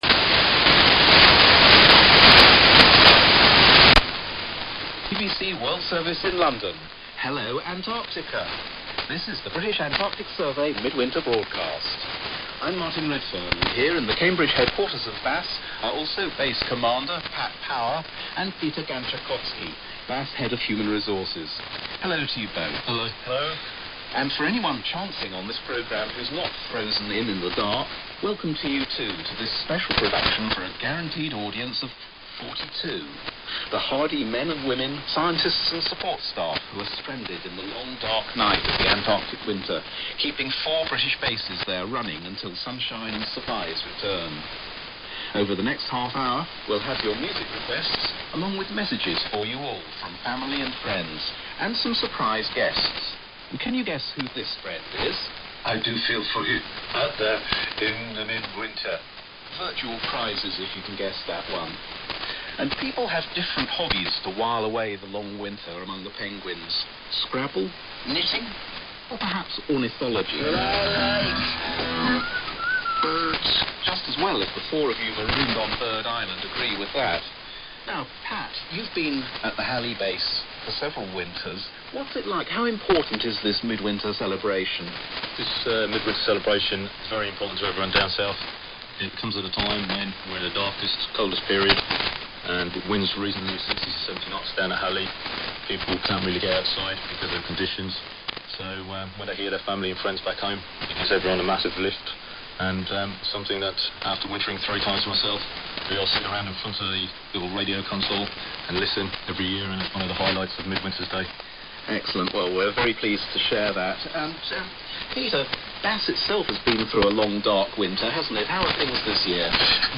I was able to receive a relatively strong signal at 21:30 UTC on 9,890 kHz from the World Service's Wooferton transmission site.